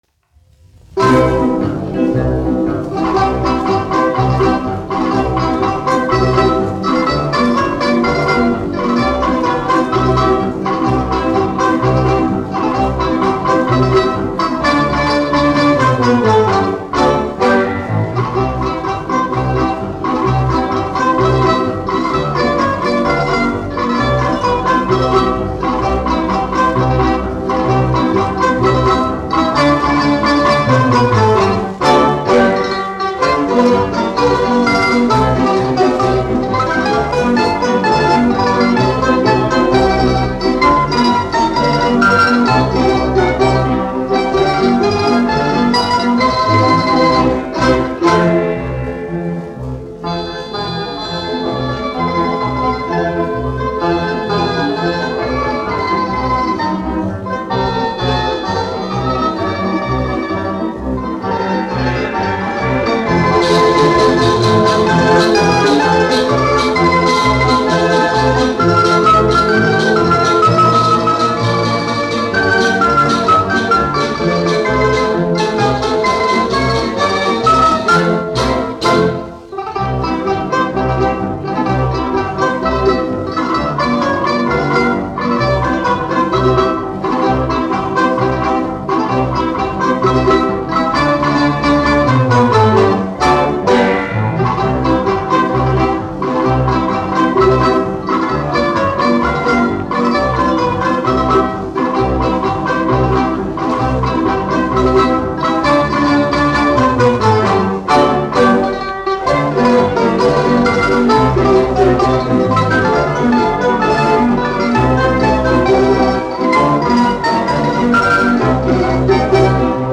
1 skpl. : analogs, 78 apgr/min, mono ; 25 cm
Balalaiku orķestra mūzika
Skaņuplate